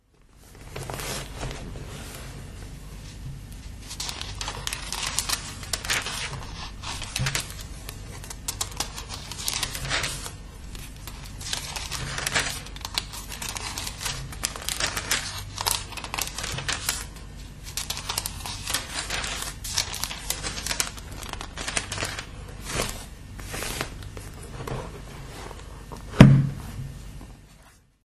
描述：翻开教会在1942年送给我父亲的《圣经》（荷兰语译本）中的尼希米书的书页。